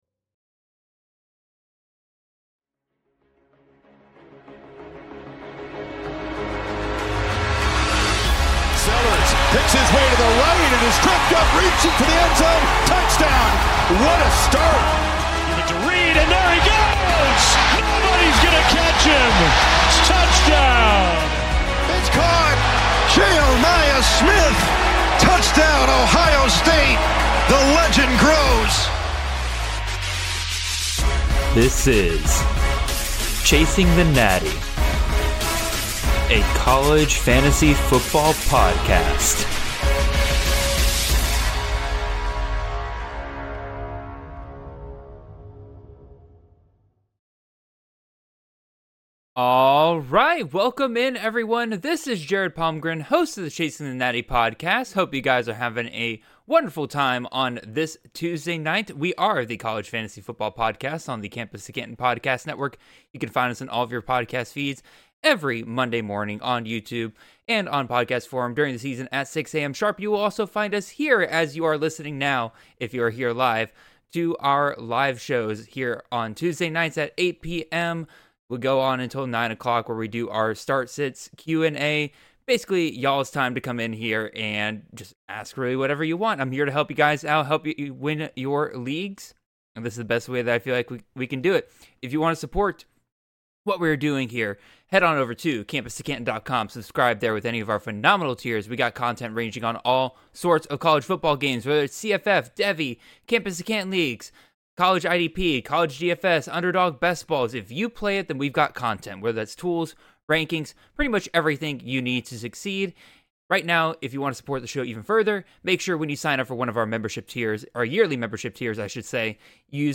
Week 2 Start/Sit Live Stream - Episode 240 - Chasing the Natty: A CFF Show